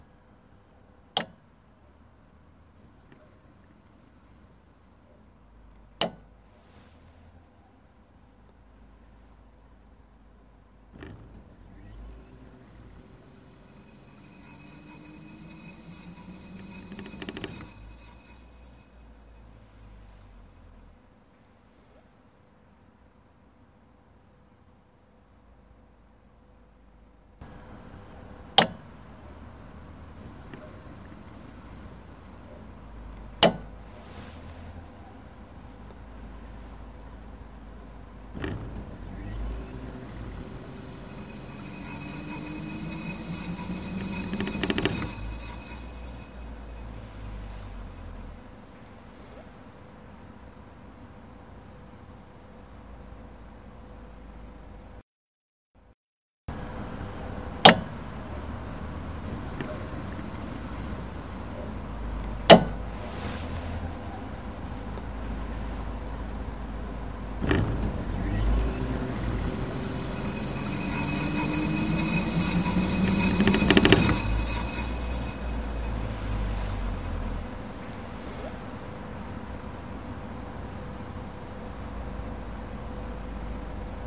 J'ai écouté le démarrage du compresseur.
on entend un premier relais, puis un second plus gros qui amène la puissance puis on entend le compresseur.
J'ai répété 3 fois l'onde sonore sur le fichier, sans amplification, amplifié 10 dB amplifiés de 20 dB
Sur votre mp3, on n'entend pas le ventilateur.
Et le démarrage du compresseur est très bref.
compresseur.mp3